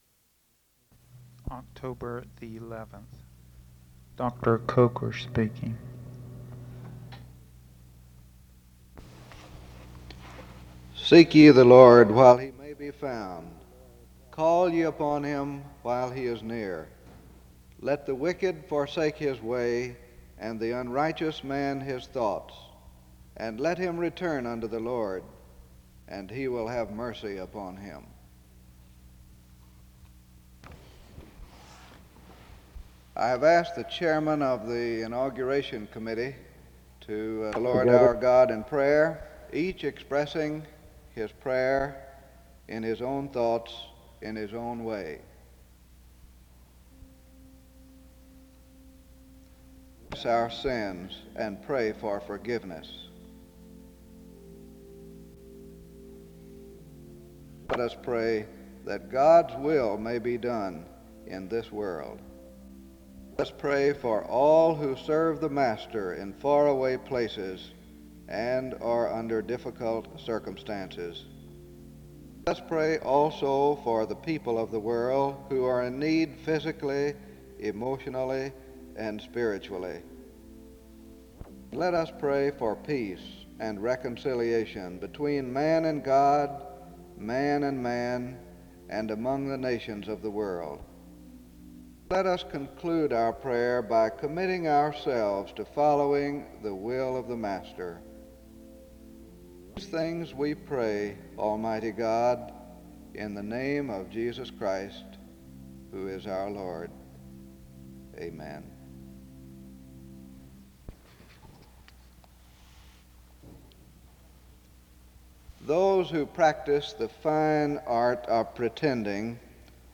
The service concludes with a song (13:51-end).
Resource type Audio Citation Archives and Special Collections, Library at Southeastern, Southeastern Baptist Theological Seminary, Wake Forest, NC.
SEBTS Chapel and Special Event Recordings SEBTS Chapel and Special Event Recordings